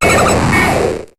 Cri de Qulbutoké dans Pokémon HOME.